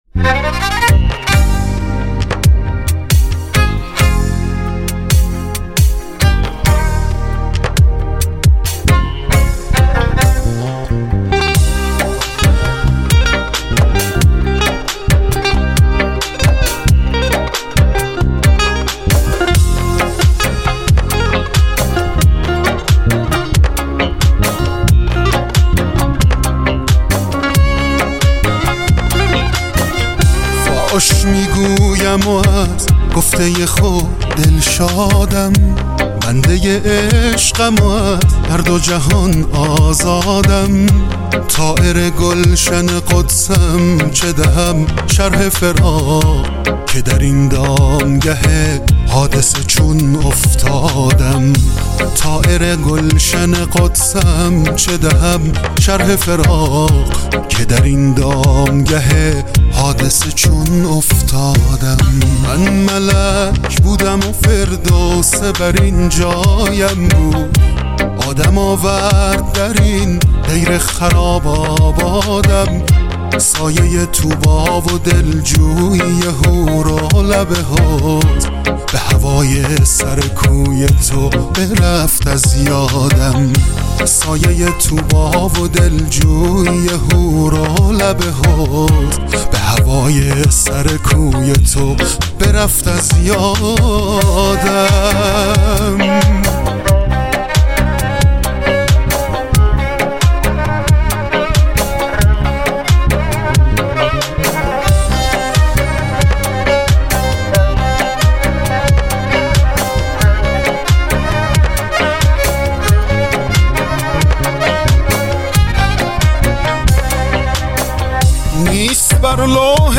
گیتار الکتریک
گیتار باس
کمانچه